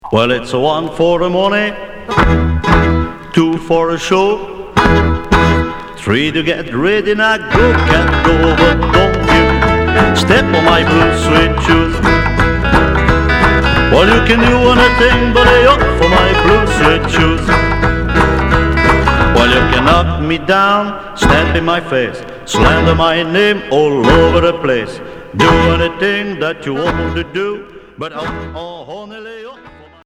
Rockabilly Unique 45t retour à l'accueil